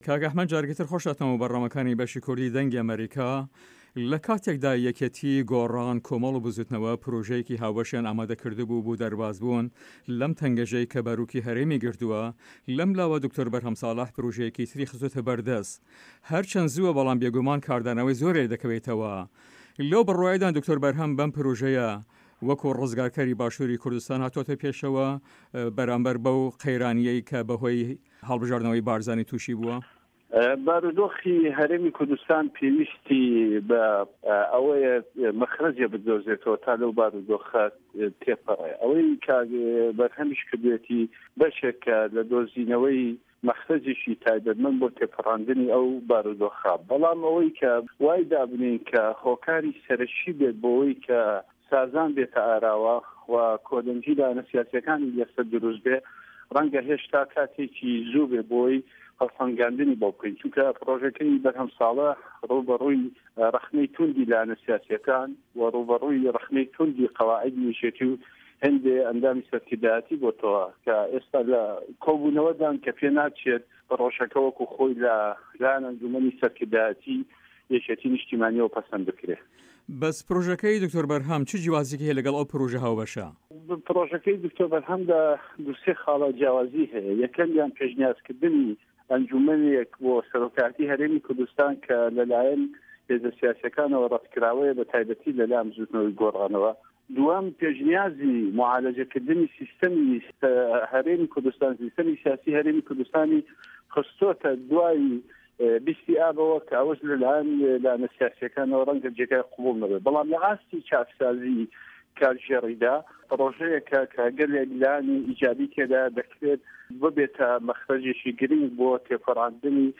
له‌ هه‌ڤپه‌یڤینێکدا له‌گه‌ڵ به‌شی کوردی ده‌نگی ئه‌مه‌ریکا